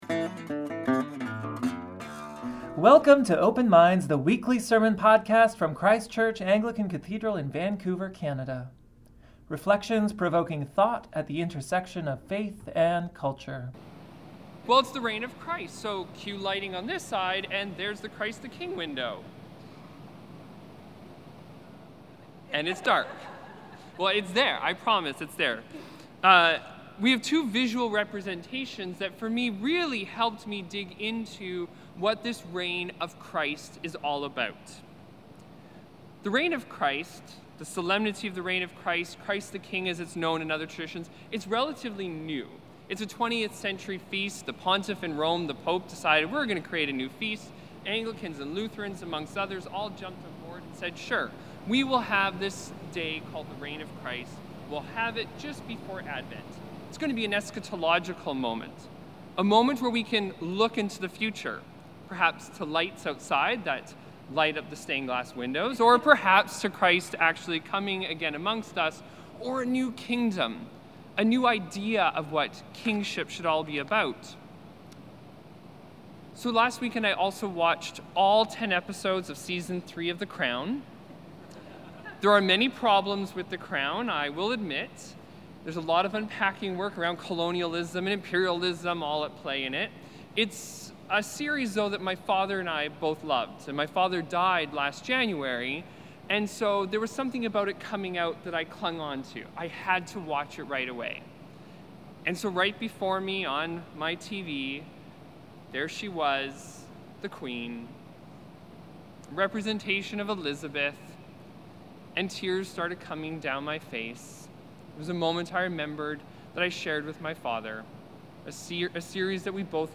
5.30pm St. Brigid's Eucharist